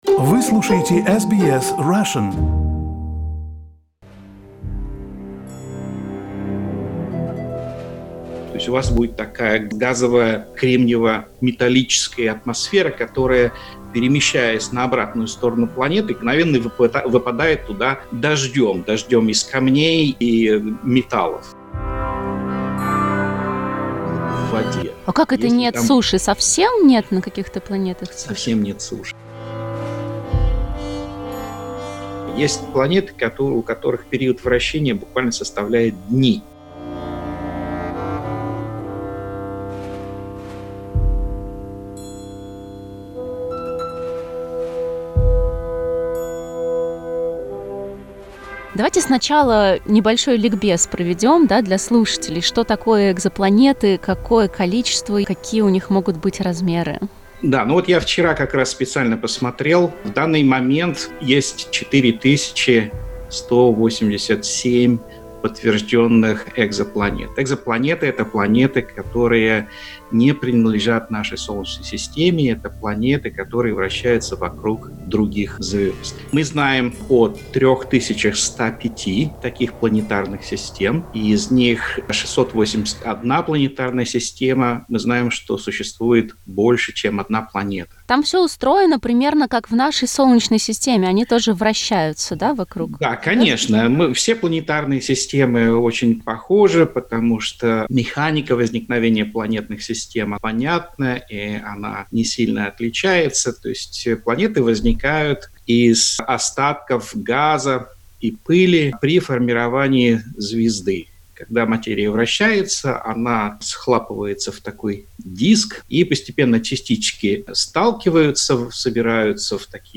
Некоторые факты из интервью: Ученым удалось подтвердить существование более 4 тысяч экзопланет.